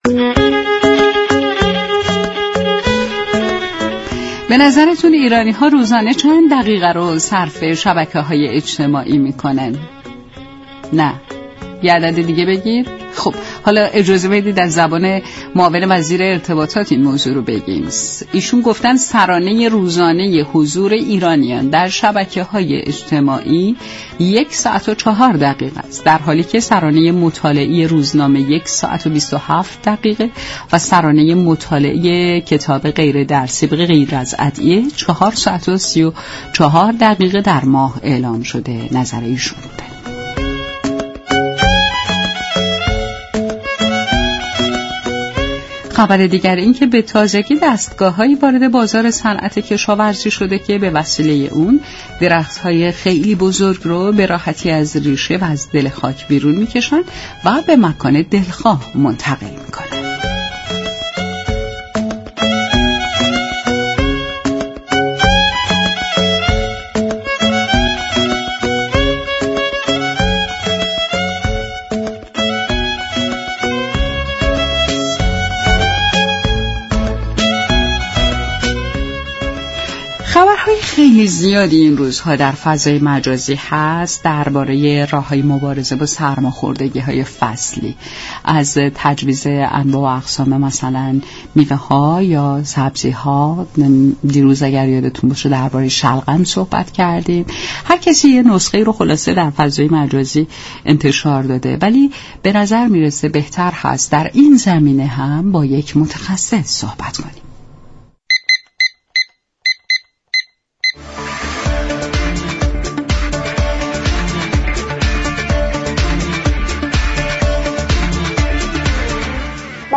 این گفت و گو را در ادامه باهم می شنویم : دریافت فایل منبع سازمانی